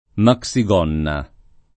vai all'elenco alfabetico delle voci ingrandisci il carattere 100% rimpicciolisci il carattere stampa invia tramite posta elettronica codividi su Facebook maxigonna [ mak S i g1 nna o mak S i g0 nna ] s. f. — cfr. gonna